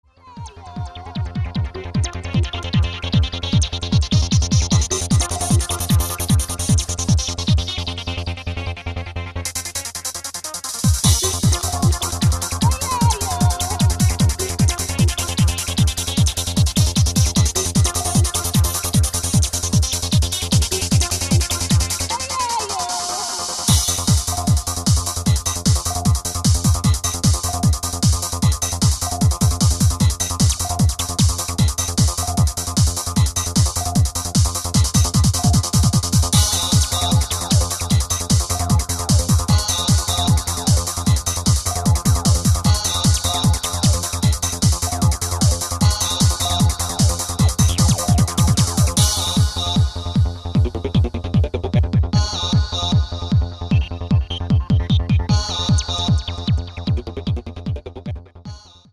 Style: Goa Trance